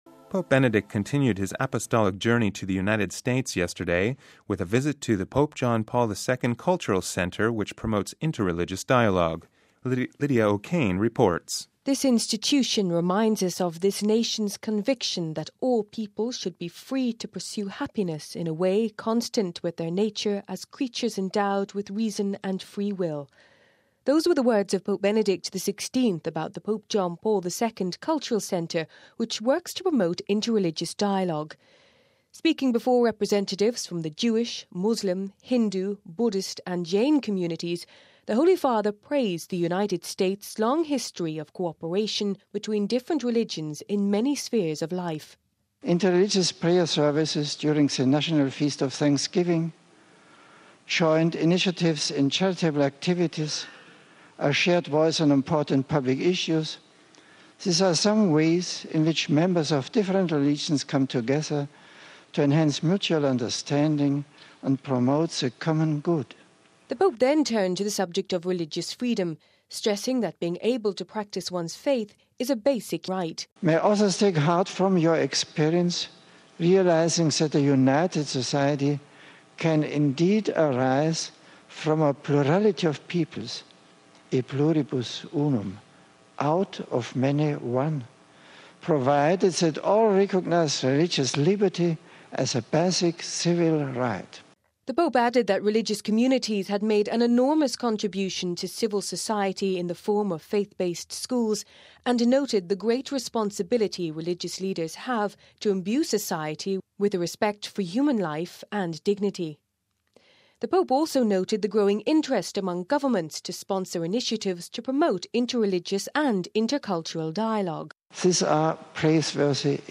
Speaking before representatives from the Jewish, Muslim, Hindu, Buddist and Jain communities the Holy Father praised the United States long history of cooperation between different religions in many spheres of life.